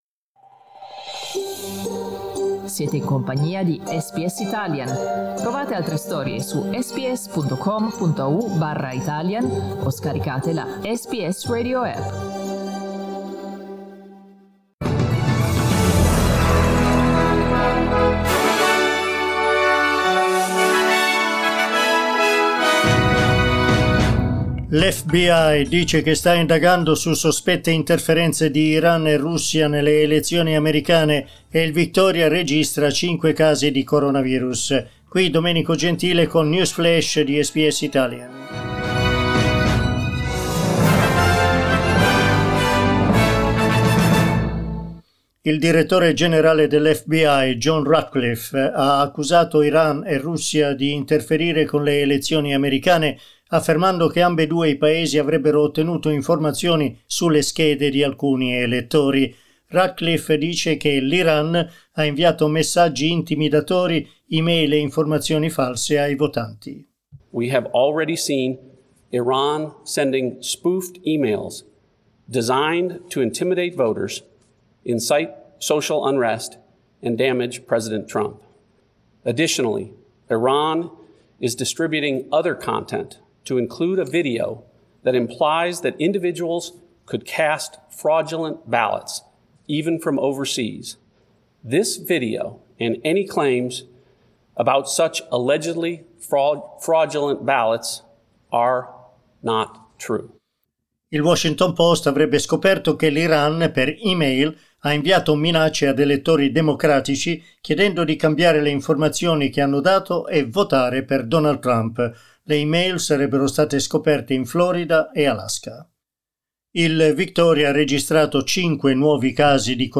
Our news update in Italian.